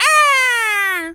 pgs/Assets/Audio/Animal_Impersonations/bird_vulture_squawk_03.wav at master
bird_vulture_squawk_03.wav